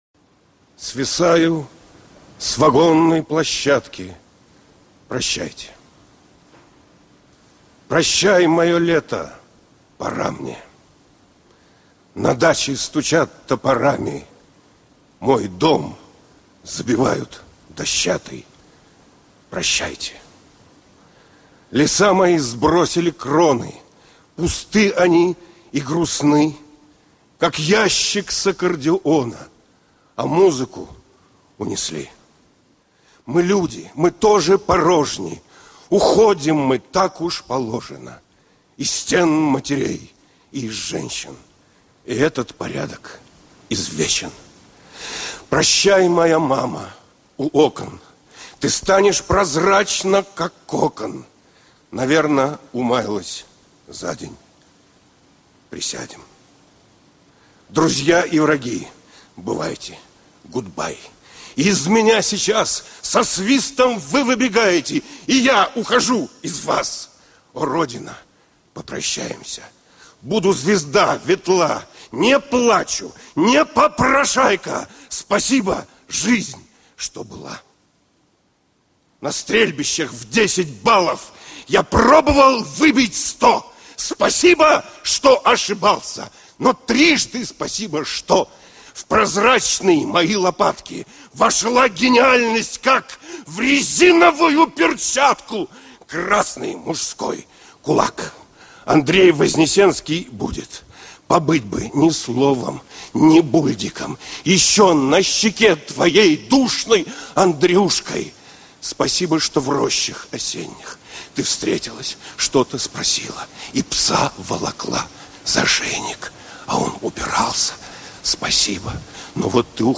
andrej-voznesenskij-osen-v-sigulde-chitaet-dmitrij-brusnikin